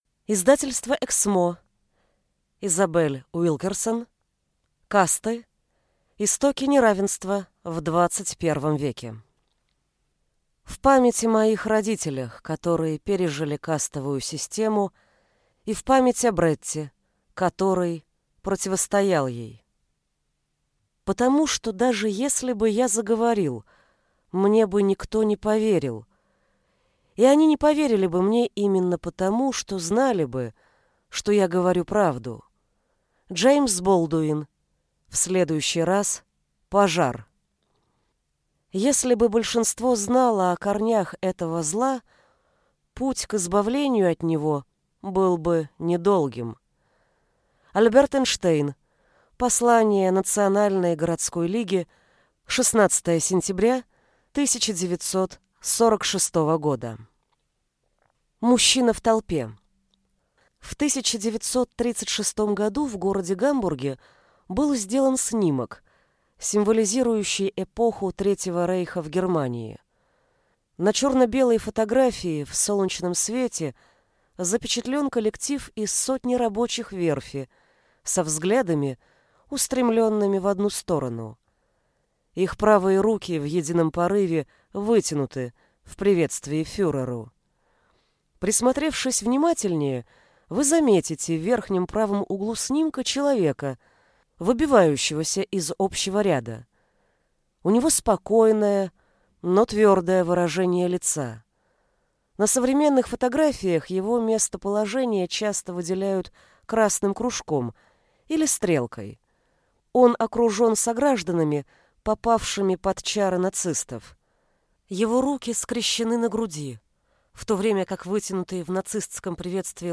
Аудиокнига Касты. Истоки неравенства в XXI веке | Библиотека аудиокниг